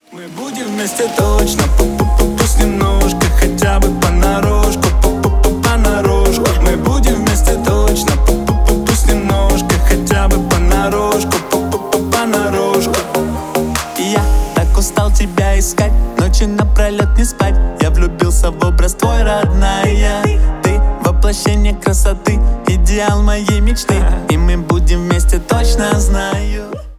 бесплатный рингтон в виде самого яркого фрагмента из песни
Поп Музыка
весёлые # милые